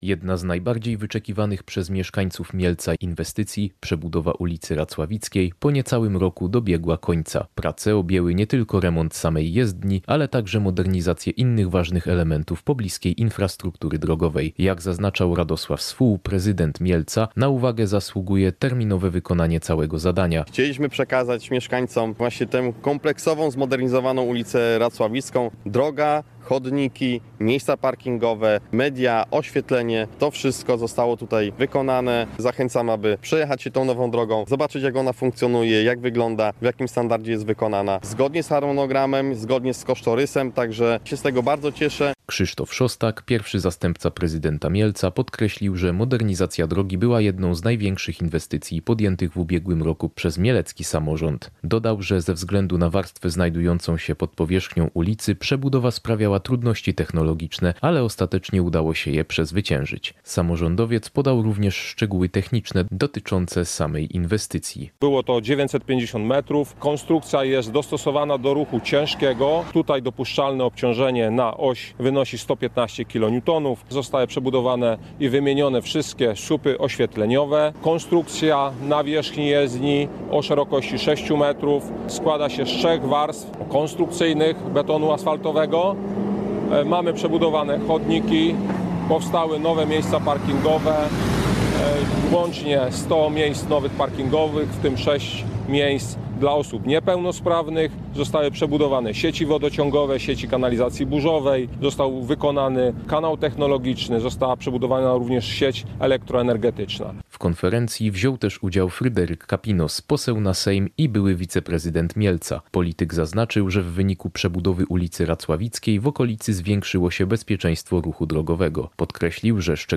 Podczas konferencji prasowej przedstawiciele mieleckiego ratusza omówili efekty zakończonych prac.